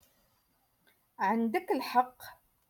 Moroccan Dialect - Rotation Two- Lesson Forty Eight